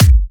VEC3 Bassdrums Trance 22.wav